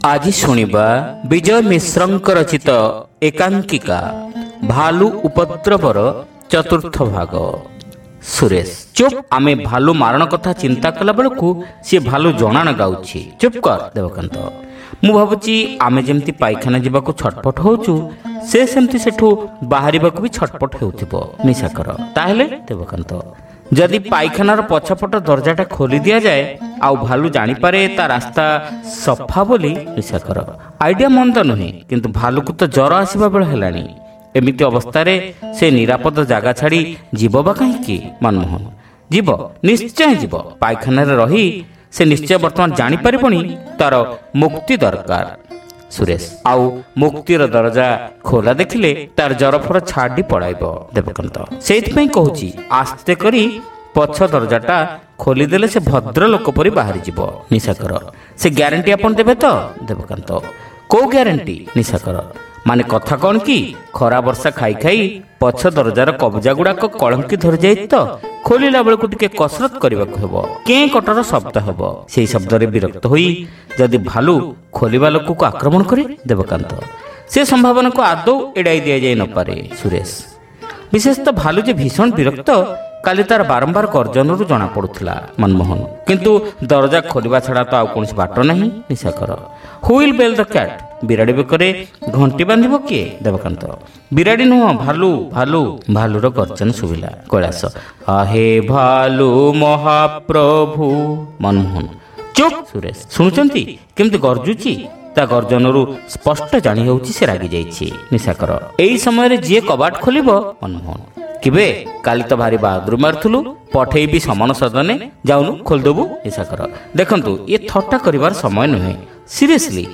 Audio One act Play : Bhalu Upadraba (Part-4)